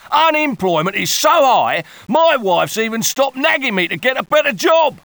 PCM 44 kHz, 16 bit, stero (905,216 bytes) - originalni zvuk CD kvalitete